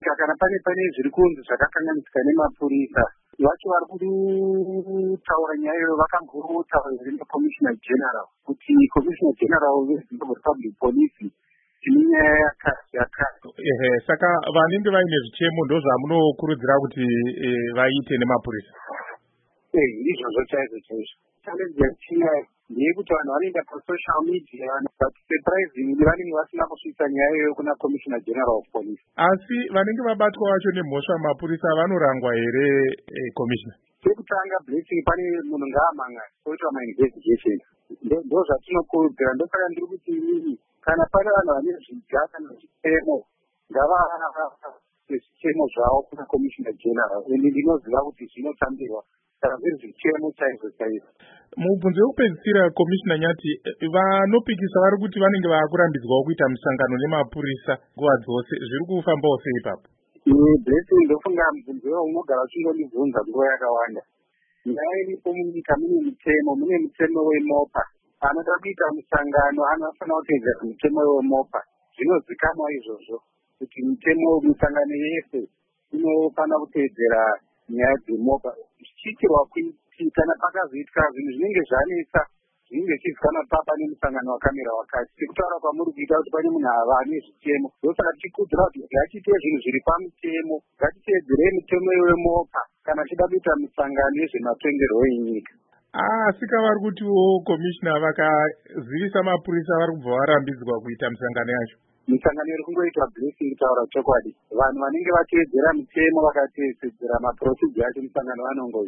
Hurukuro naCommissioner Paul Nyathi